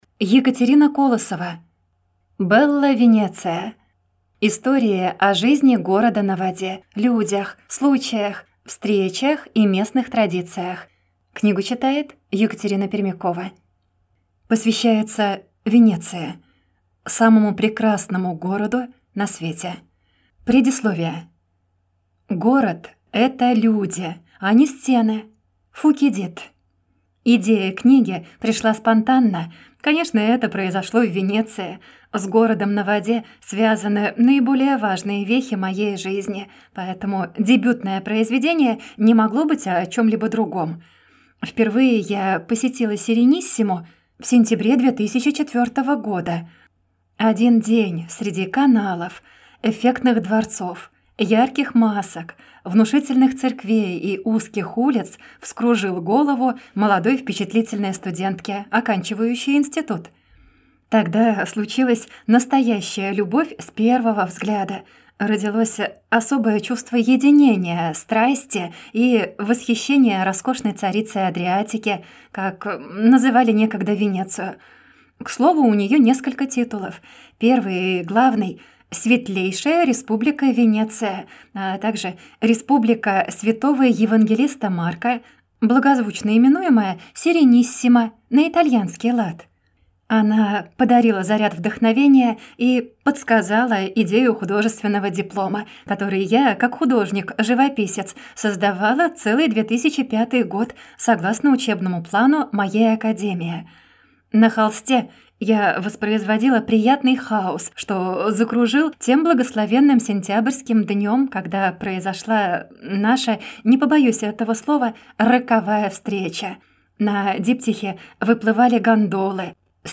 Аудиокнига Bella Венеция! Истории о жизни города на воде, людях, случаях, встречах и местных традициях | Библиотека аудиокниг